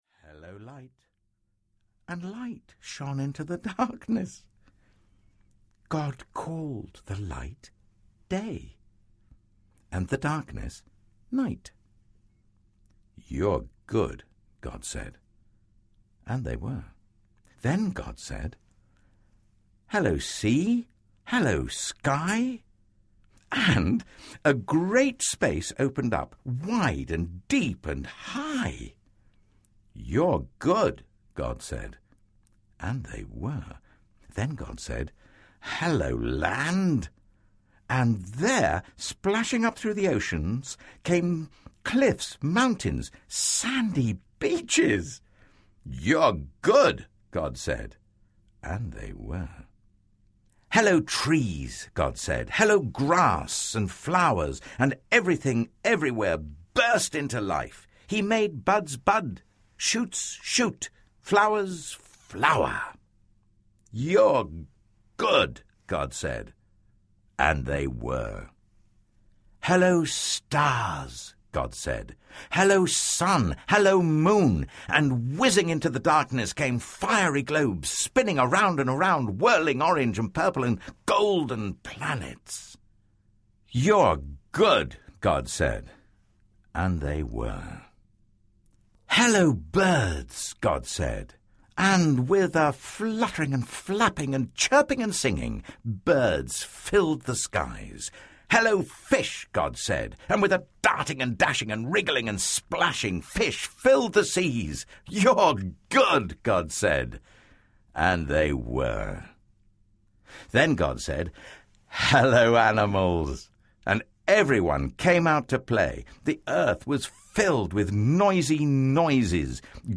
The Jesus Storybook Bible: Every Story Whispers His Name Audiobook
Narrator
3.3 Hrs. – Unabridged